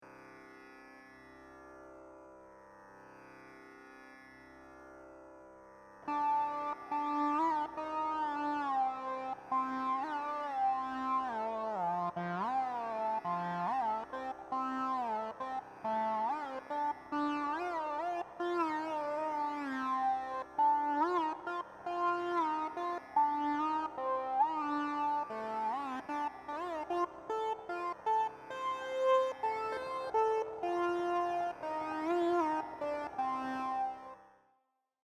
Here is another - required a lot of effort + many takes + lot of editing (i.e. took snippets from different takes and stitched) + some "effects" (with garageband).
The sound in the abhogi isn't unlike a gottu vadyam's.